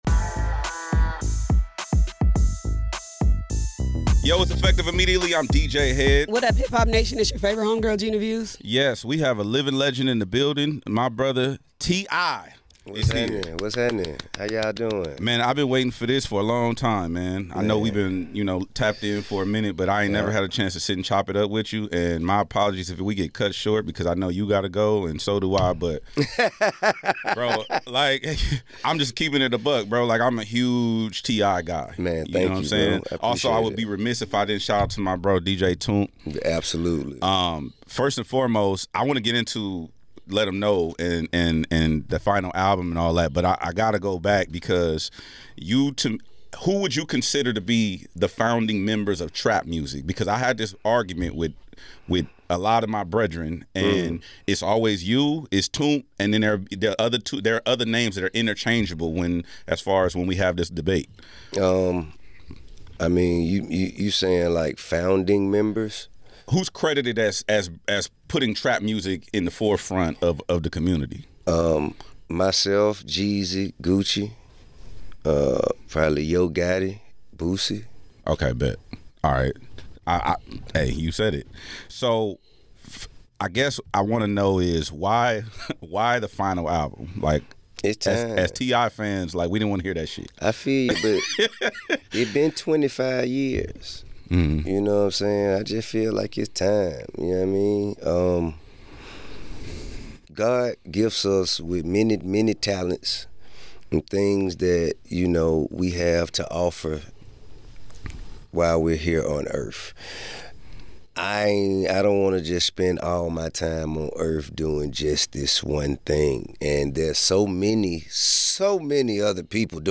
Effective Immediately is a nationally syndicated radio show and podcast that serves as the ultimate destination for cultural conversations, exclusive interviews, and relevant content.